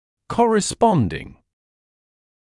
[ˌkɔrɪ’spɔndɪŋ][ˌкори’спондин]соответствующий